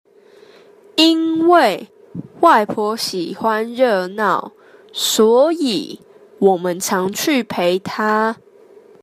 (請先仔細聆聽老師唸，再來，請你試著唸唸看。)